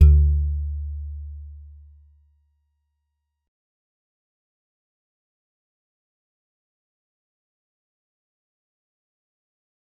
G_Musicbox-D2-mf.wav